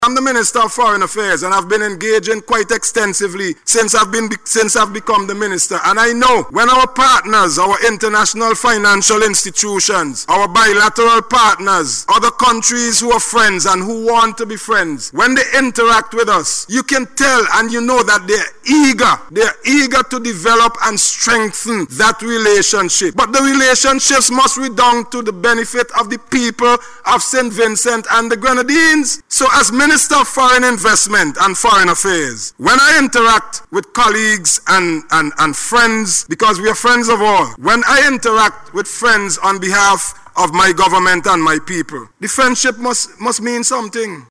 Speaking in Parliament recently, Minister Bramble said his engagements with international partners and financial institutions have revealed a strong willingness to deepen cooperation.